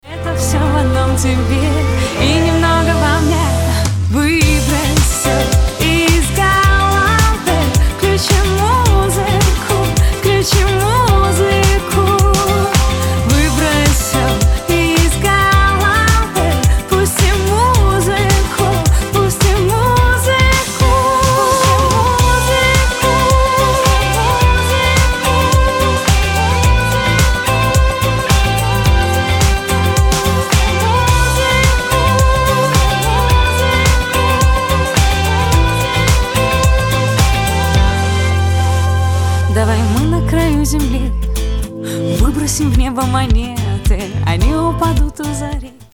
• Качество: 320, Stereo
позитивные
ритмичные
зажигательные
женский голос
пианино